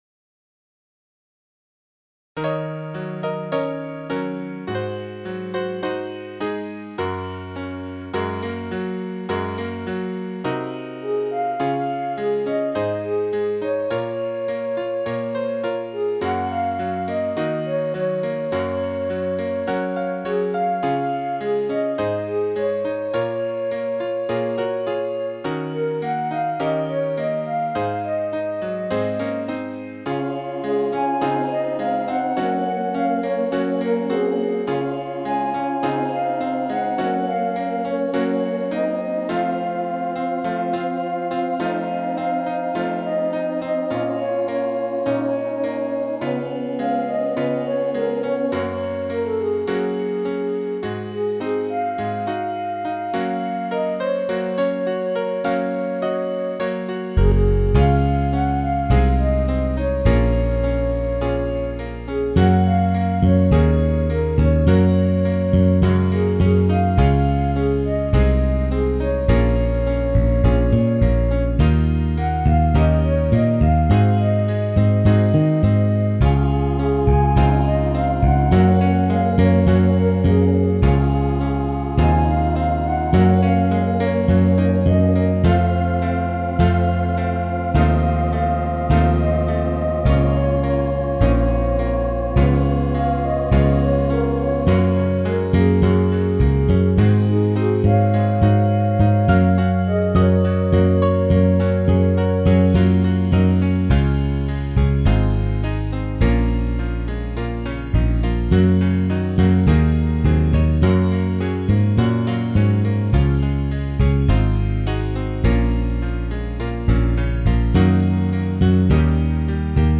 AGは厄介なのでピアノを軸に書き換えしている。
この時点でAPに更に尾ひれ（オブリガード）とソロで
「エレクトリックグランドピアノ」を重ねることは覚悟の上。
って、APもMIDIにしている時点で「エレクトリック」だっつうの。